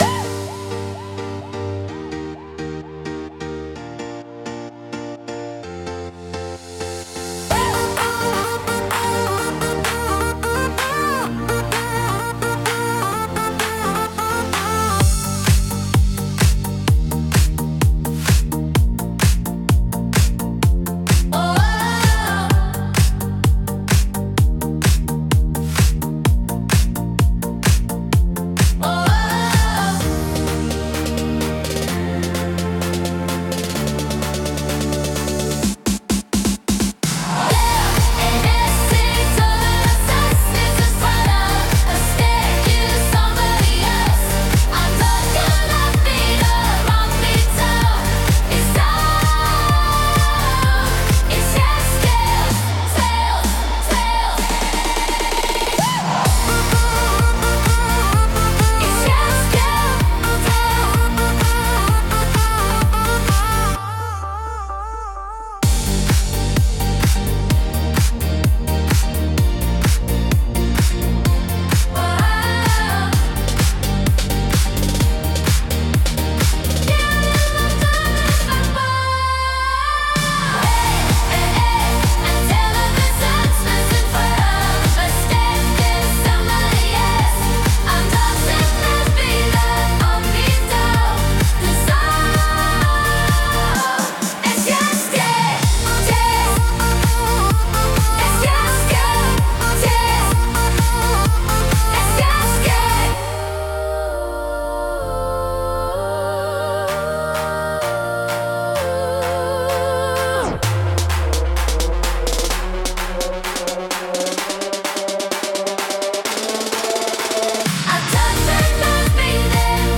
聴く人の気分を高め、集中力とパワーを引き出すダイナミックなジャンルです。